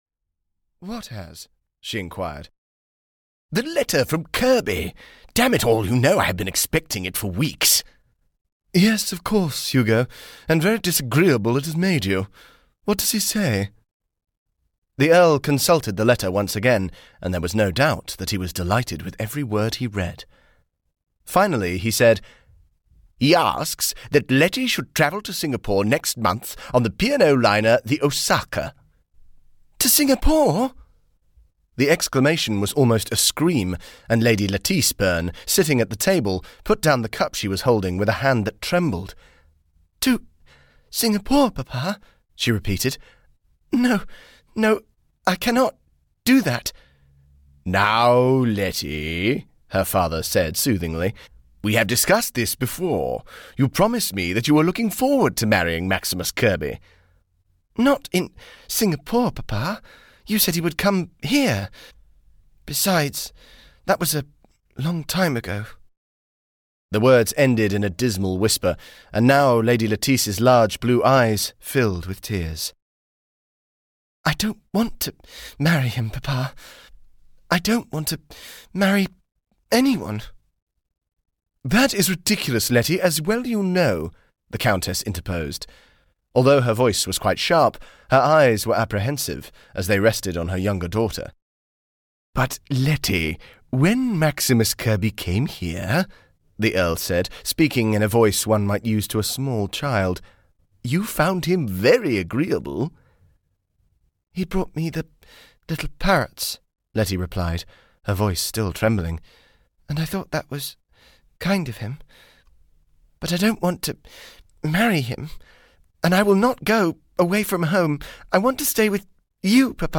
The Magnificent Marriage (EN) audiokniha
Ukázka z knihy